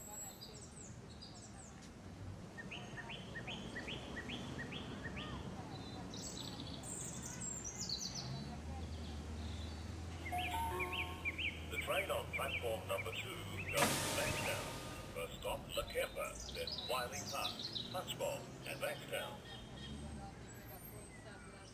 Ambiance Gare de campagne (Broadcast) – Le Studio JeeeP Prod
Bruits d’ambiance d’une gare de campagne.
Ambiance-de-gare.mp3